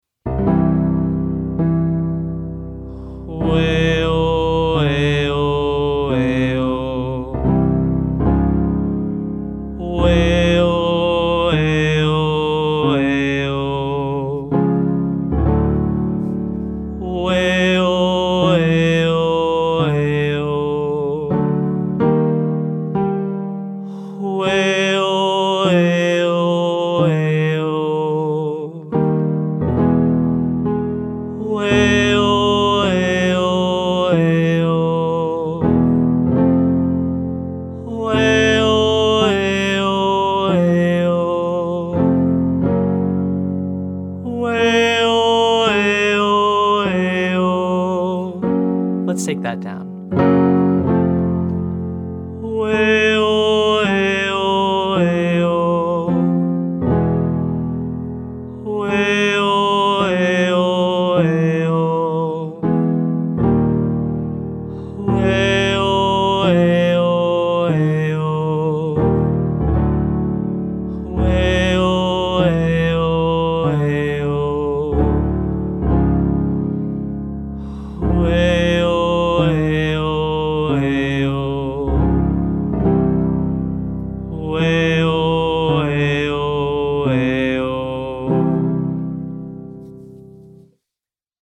Day 4: Vocal Tension Release - Online Singing Lesson